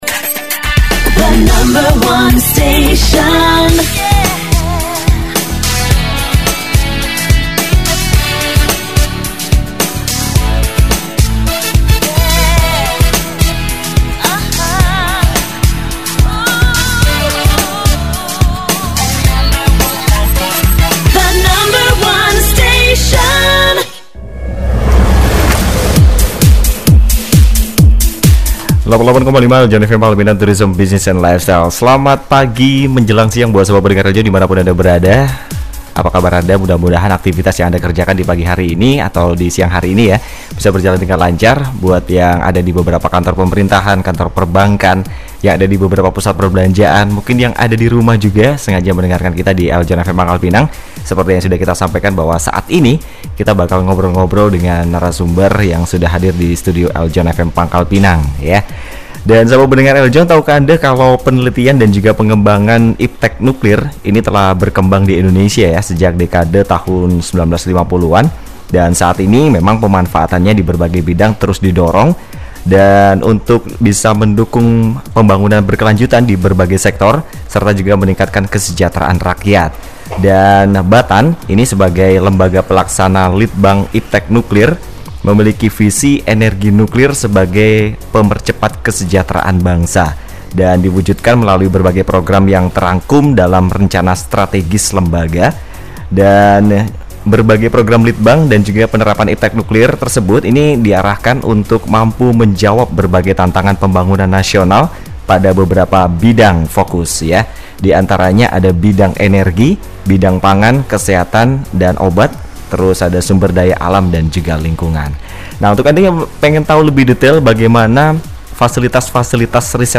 REKAM SIAR TALKSHOW BATAN DI RADIO EL JOHN PANGKAL PINANG, JUDUL : RISET NUKLIR DI INDONESIA - Repositori Karya
AUDIO_TALKSHOW BATAN DI RADIO EL JOHN PANGKAL PINANG_MENGENAL FASILITAS RISET NUKLIR DI INDONESIA_PDK_2013.mp3